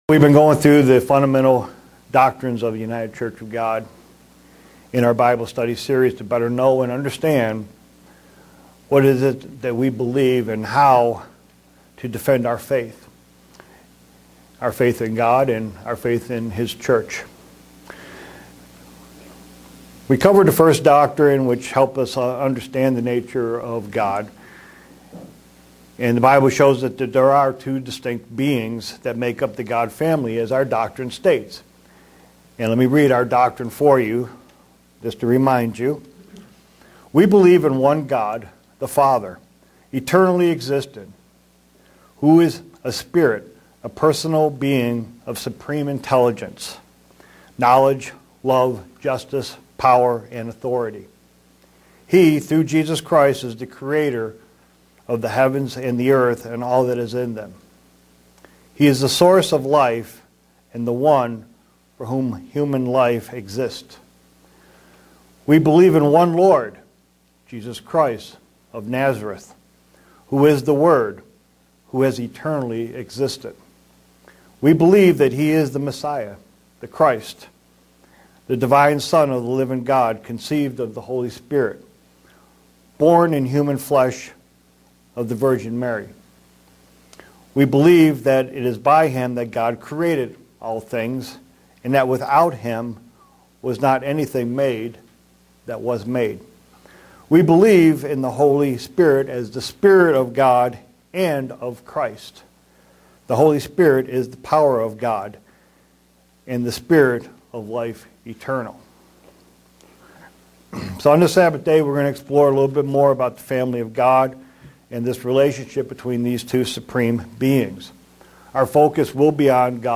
Given in Buffalo, NY
Print Exploriing the Family of God and the relationship between God the Father and Jesus Christ. sermon Studying the bible?